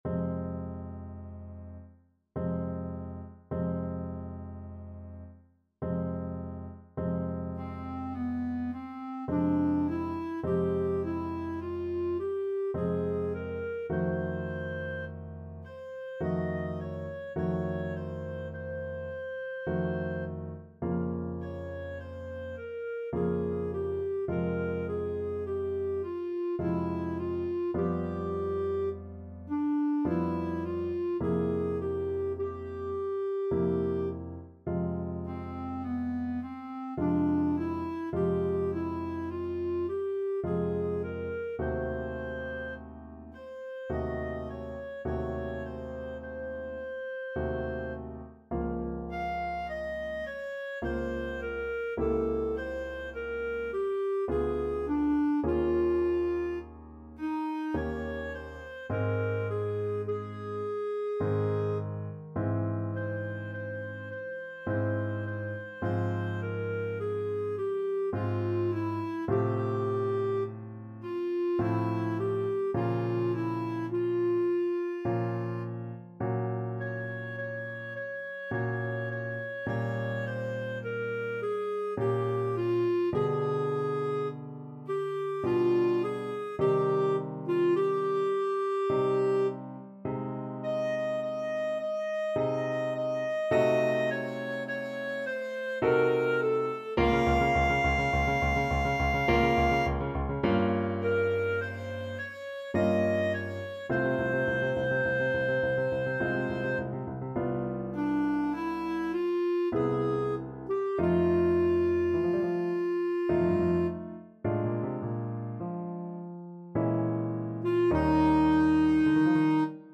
Clarinet version
Classical Clarinet
Tempo Marking: Andante molto moderato ( =66) ~ = 52 Score Key: F minor (Sounding Pitch) G minor (Clarinet in Bb) ( View more F minor Music for Clarinet ) Range: B4-F6 Time Signature: 3/4 ( View more 3/4 Music ) Duration: 2:21 Numb